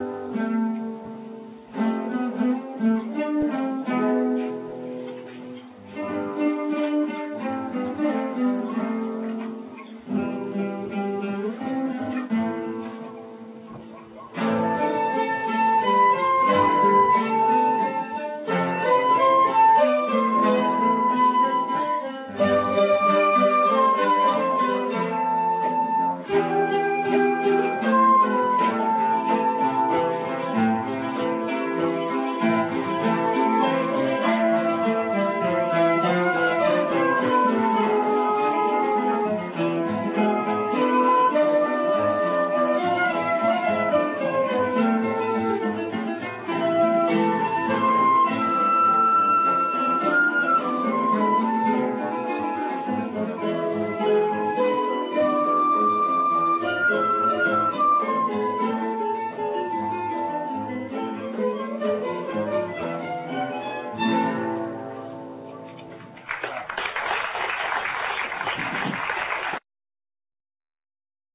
ΑΠΟ ΤΗΝ ΣΥΝΑΥΛΙΑ
ΦΛΑΟΥΤΟ ΚΑΙ ΚΙΘΑΡΕΣ